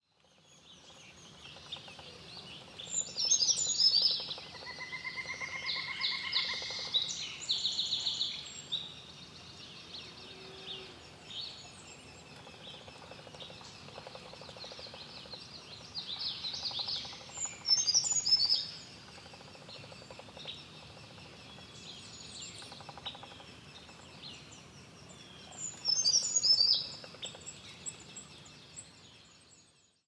Grimpereau brun – Certhia americana
Chant Écoutez le chant aigü. Cantons-de-l’Est, QC. 22 juin 2018. 7h00.